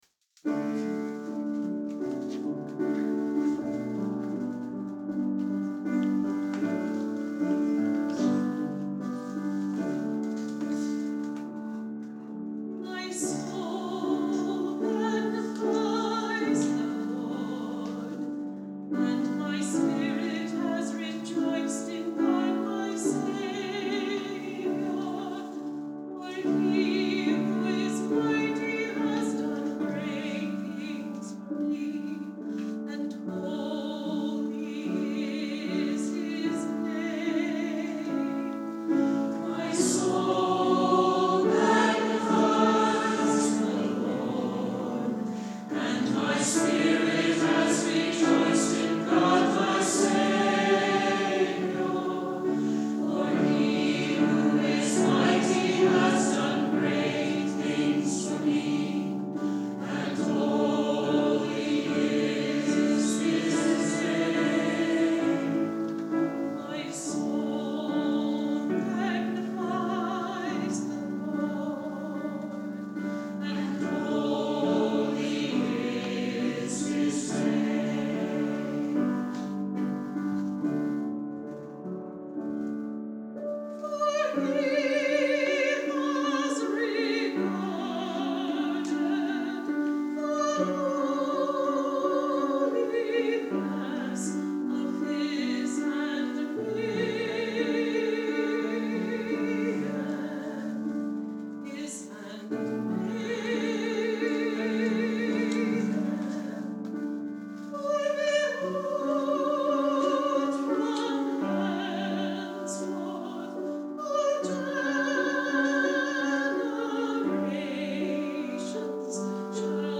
Today I’m posting a remastered recording of the choral version of Mary’s Song, recorded at All Saints Church on Christmas Eve 2016.